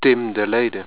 Pronounced